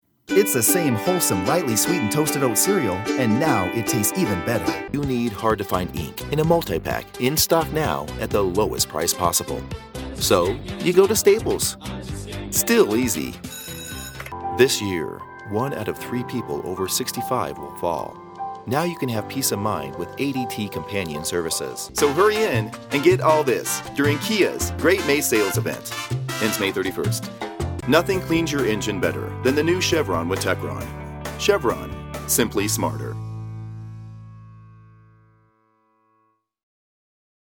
I have a professional home studio and provide clients with quality reads and fast turnaround.
Expert copy interpretation and delivery in a manner appropriate to both the subject matter and the audience.
Kein Dialekt
Sprechprobe: eLearning (Muttersprache):